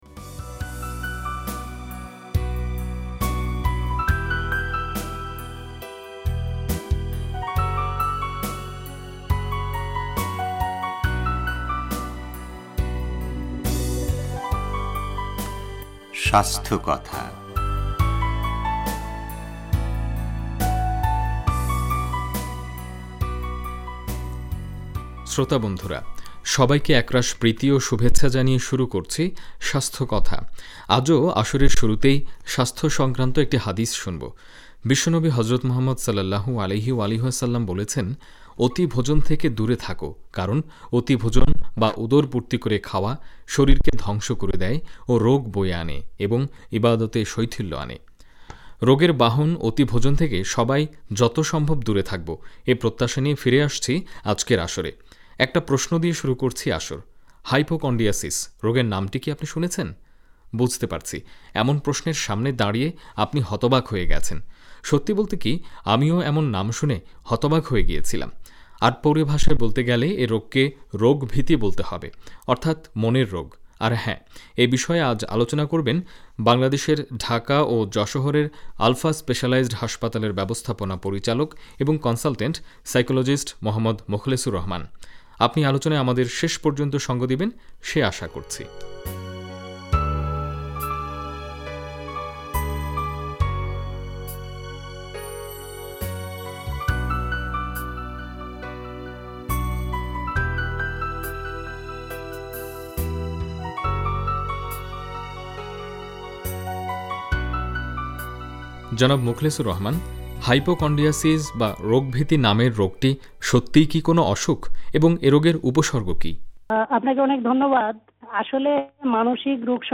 রেডিও তেহরান